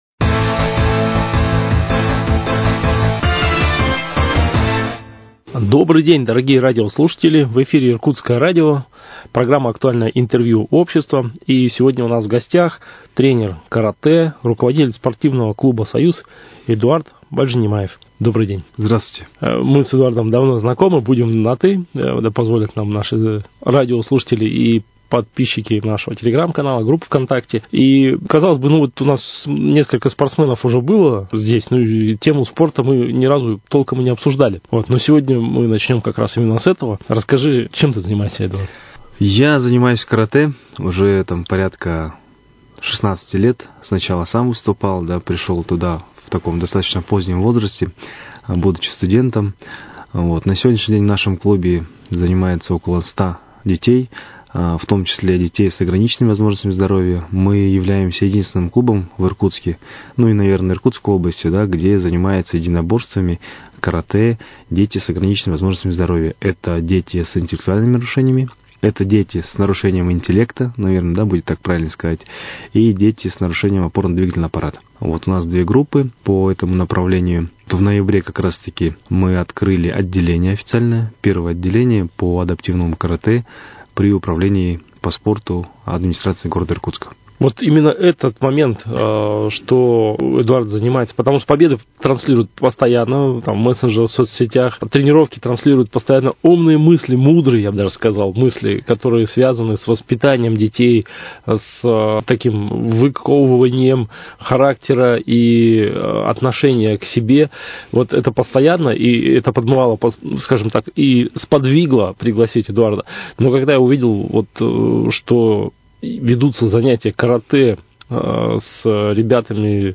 Актуальное интервью
Сегодня в гостях у Иркутского радио тренер по карате